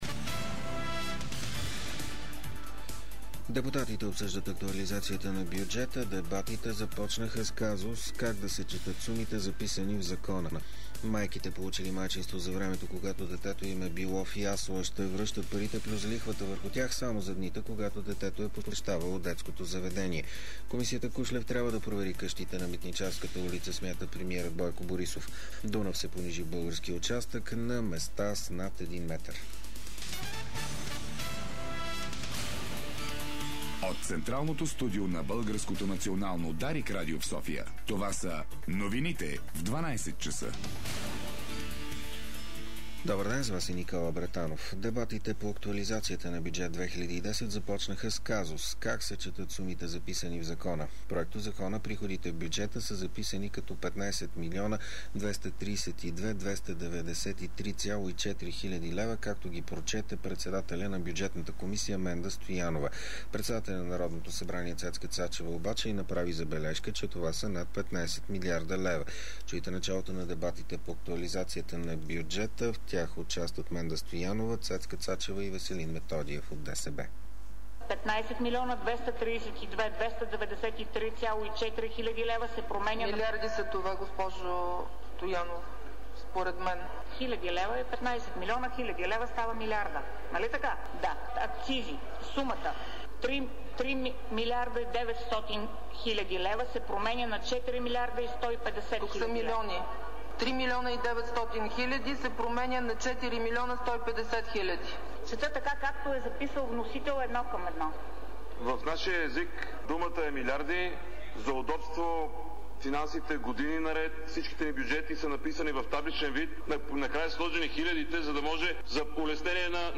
Обедна информационна емисия - 08.07.2010